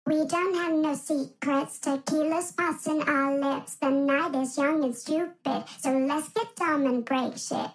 91V_AP_120_vocal_hook_fx_spoken_no_secrets_pitched_dry